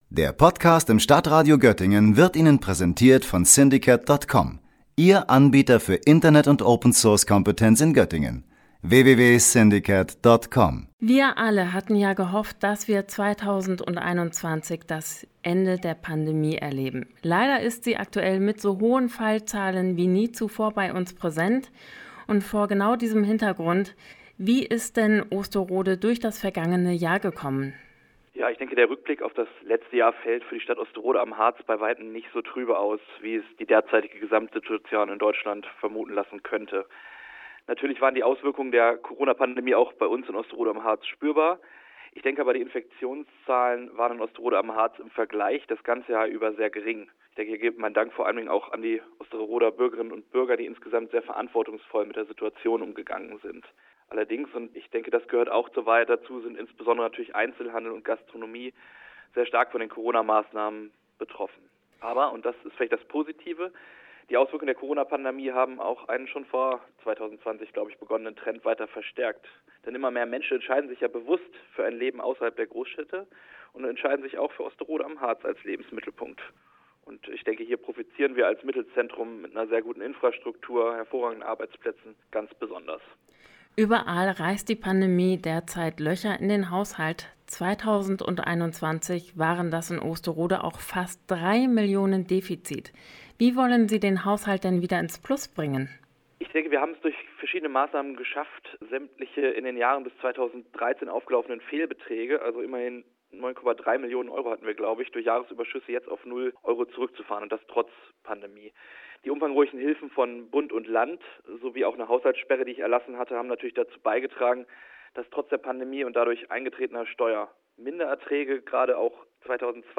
Beiträge > ALOHA, Pandemie und Haushaltsdefizit – Osterodes Bürgermeister Jens Augat im Interview - StadtRadio Göttingen